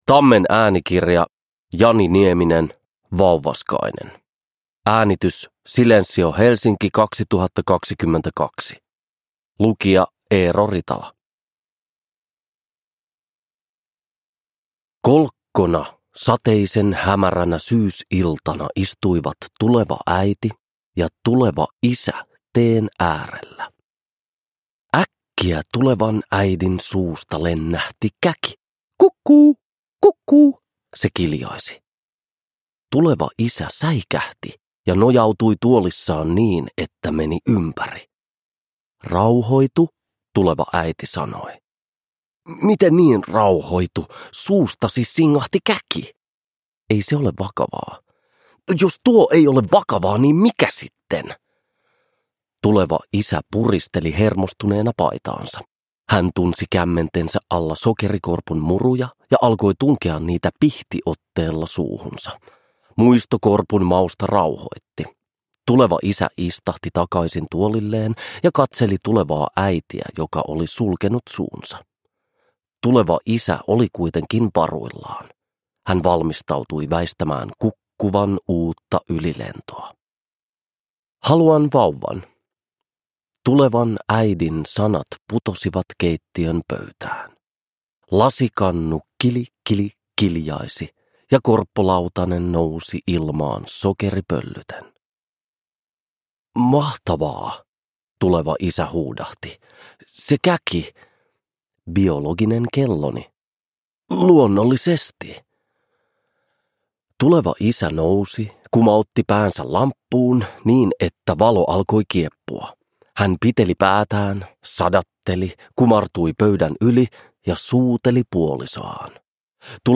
Vauvaskainen – Ljudbok – Laddas ner
Uppläsare: Eero Ritala